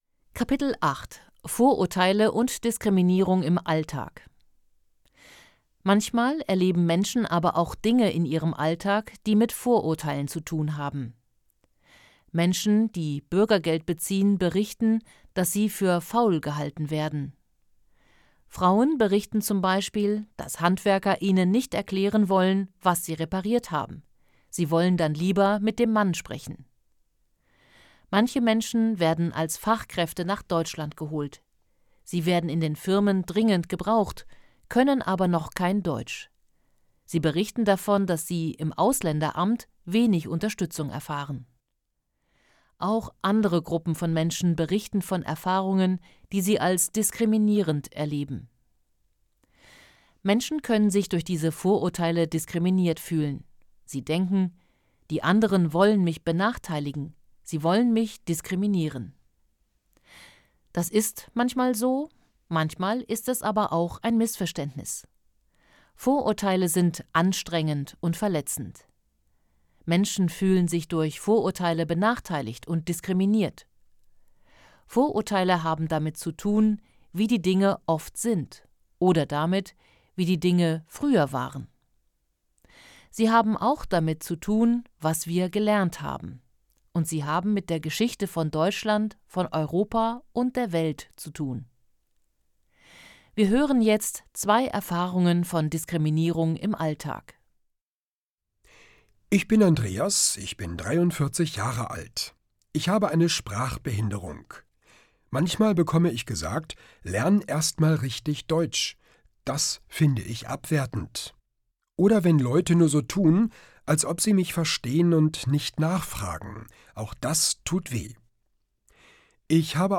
Kapitel 8: Vorurteile und Diskriminierung im Alltag Hörbuch: „einfach POLITIK: Zusammenleben und Diskriminierung“
• Produktion: Studio Hannover